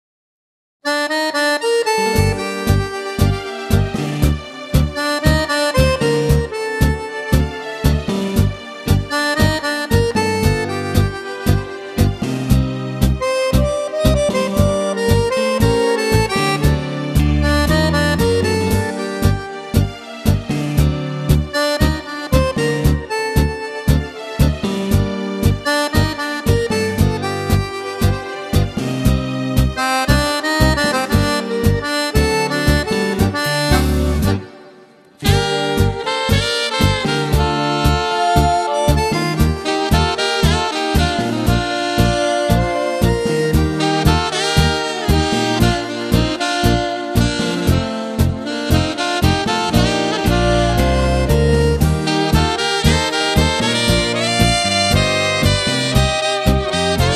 Tango
13 brani per orchestra.